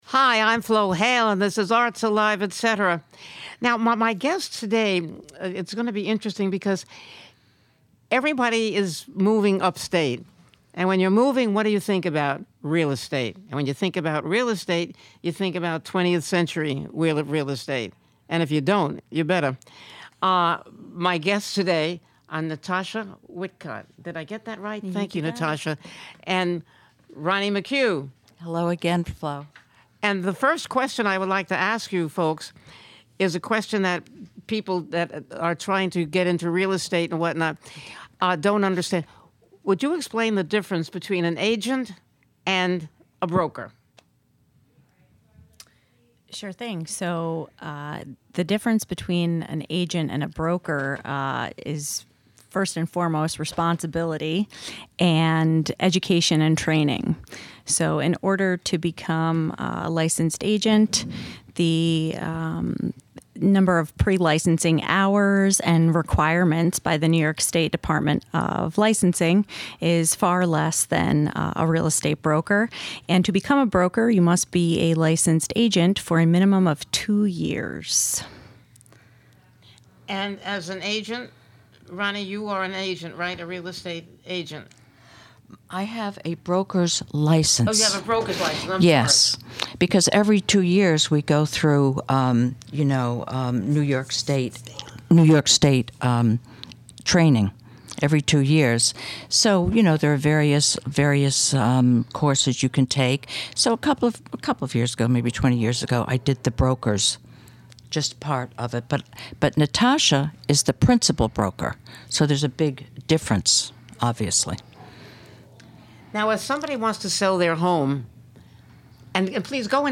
With a wicked wit and a true heart she banters with fellow singers and thespians, local luminaries and mover/shakers and knows how to get them to reveal what makes them tick.